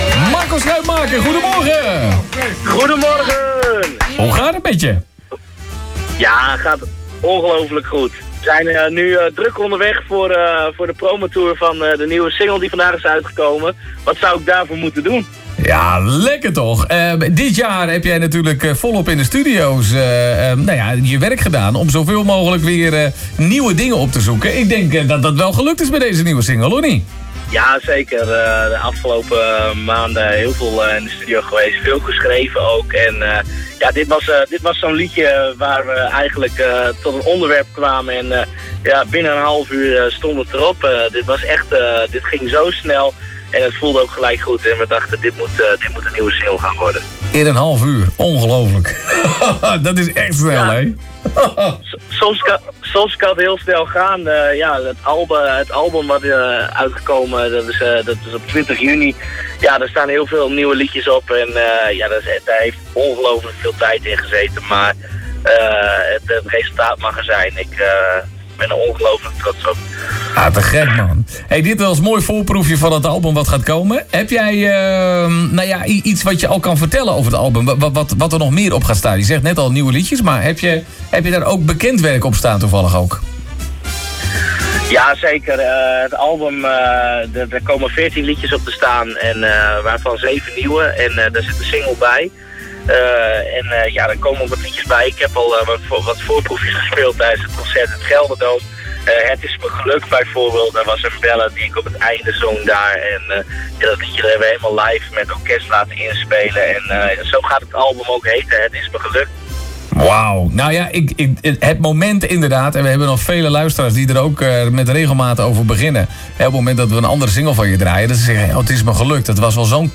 BELLEN